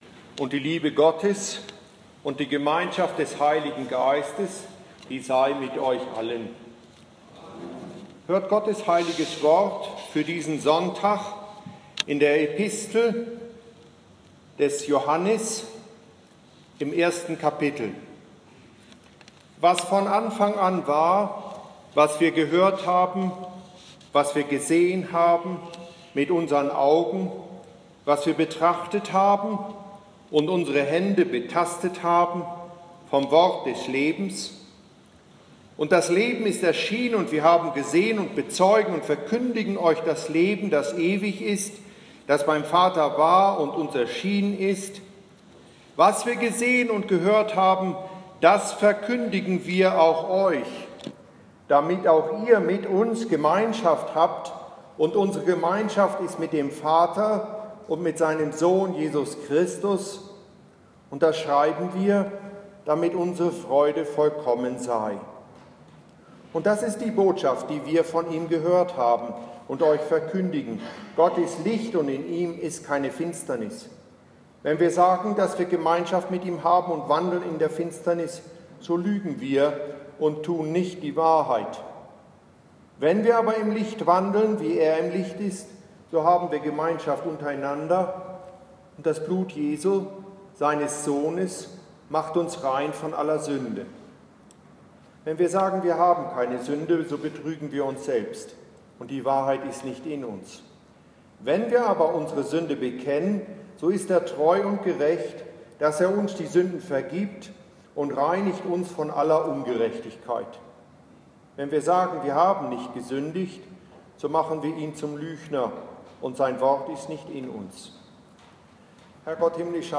Und hier meine Predigt dazu: